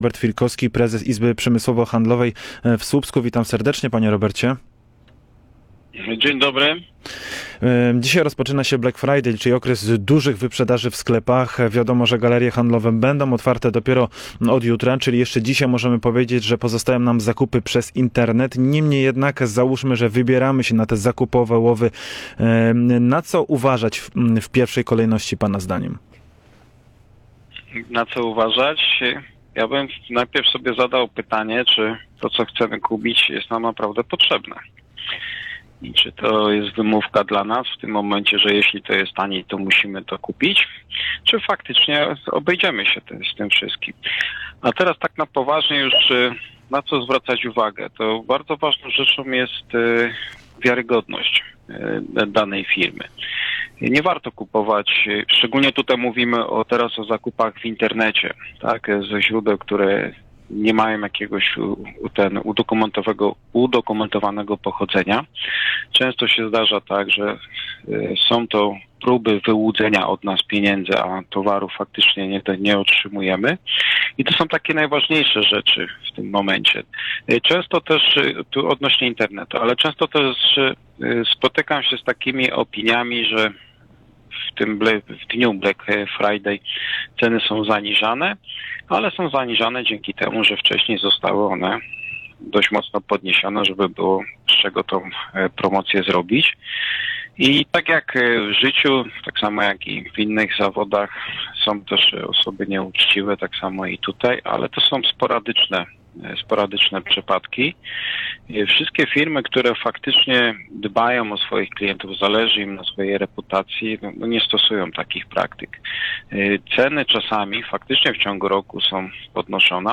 Zapytaliśmy słupszczan, czy skorzystają z tych piątkowych wyprzedaży.